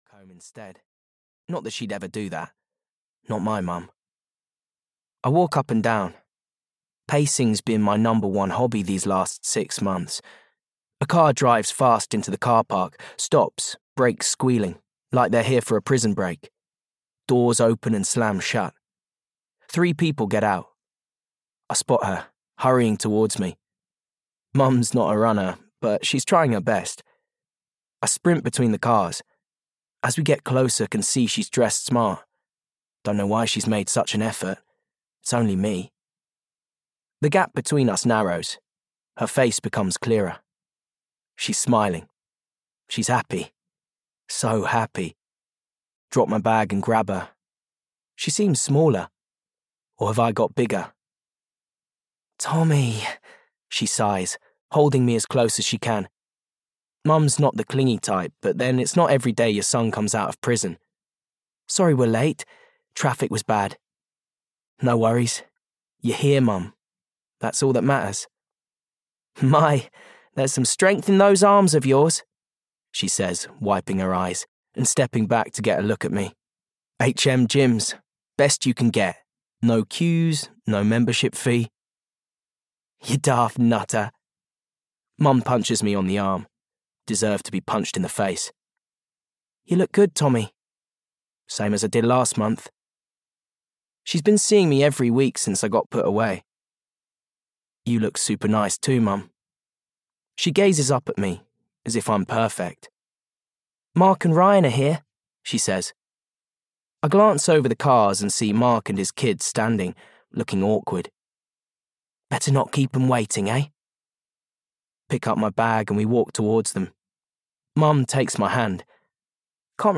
Read Between the Lies (EN) audiokniha
Ukázka z knihy